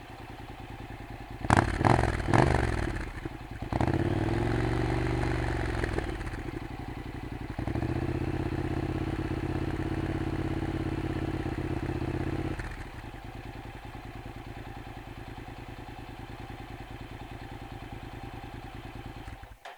Soundfile IXIL Hyperlow XL an NC 700X - Honda NC Forum
Datei „ixil_hyperlow_xl.mp3“ herunterladen